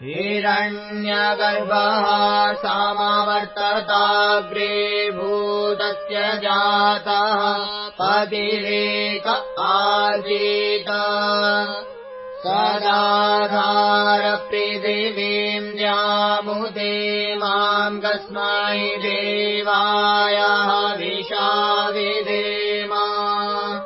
Vedic chanting
मन्त्र उच्चारण--